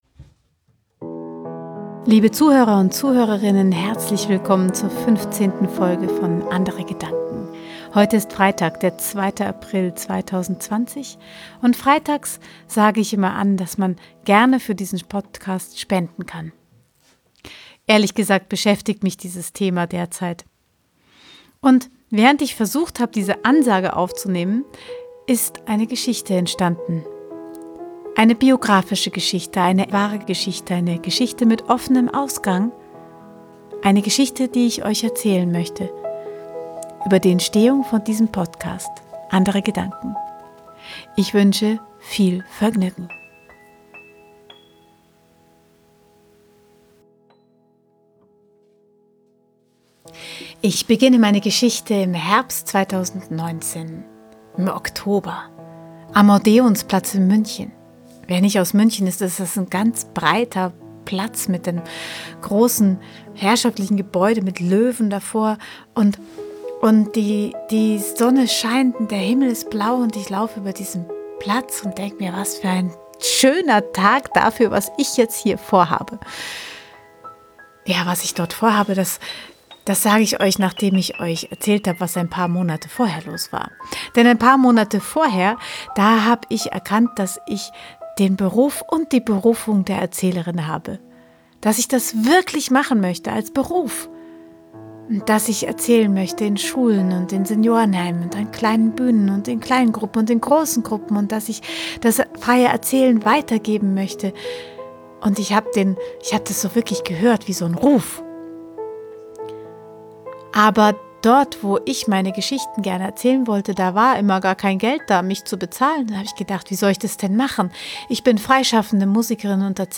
frei erzählte Geschichten, Musik, Inspiration